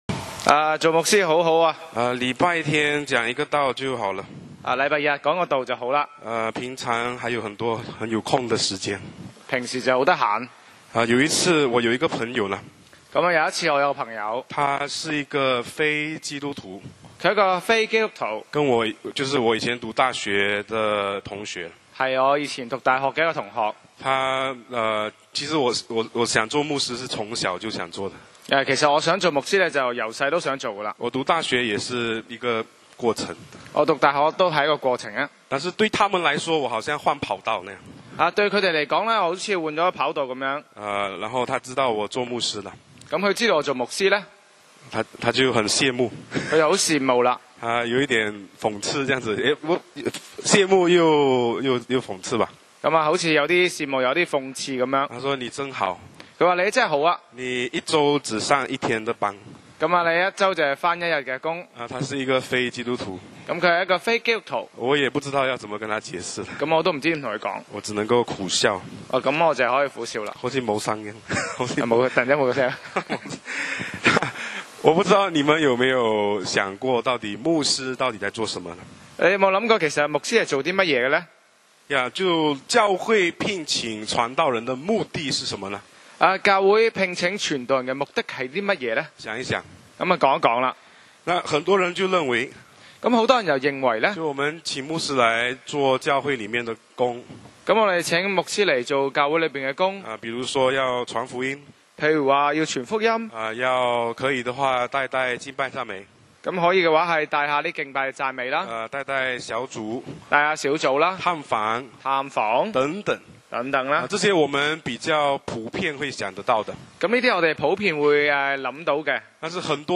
講道 Sermon 題目 Topic：牧师到底在做什么？